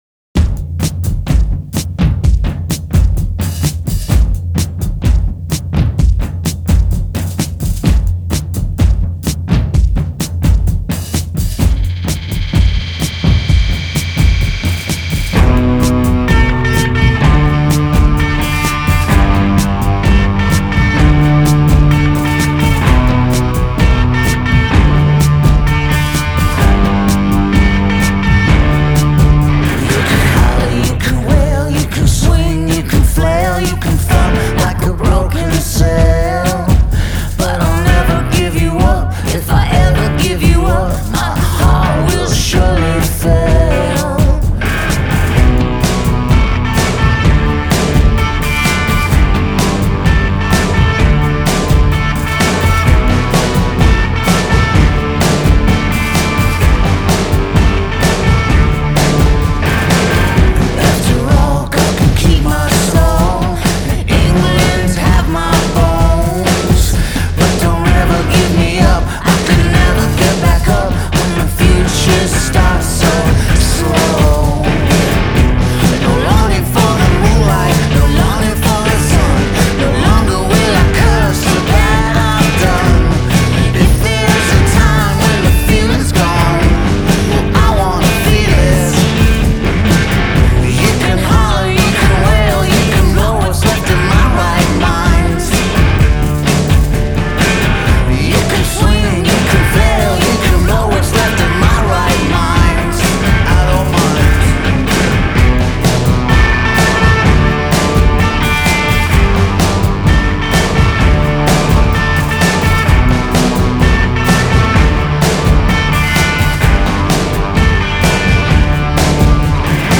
raw lyrics and sharp vocals.